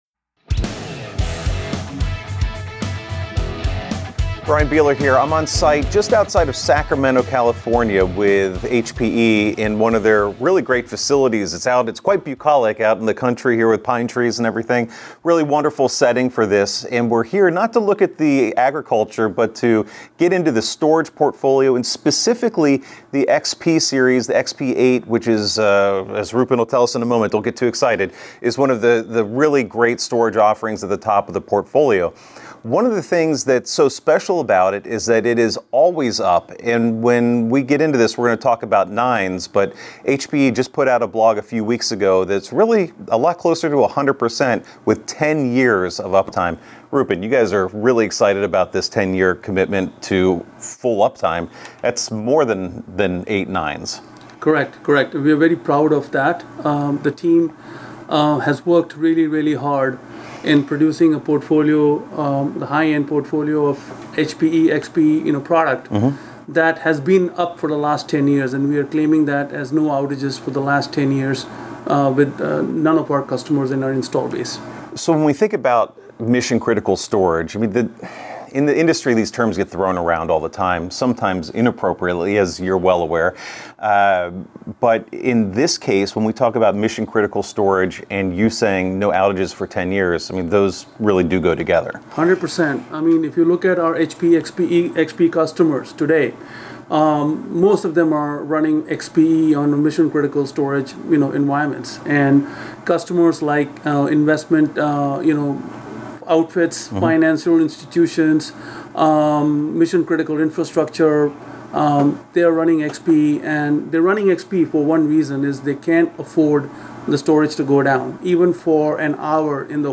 这是一场引人入胜的讨论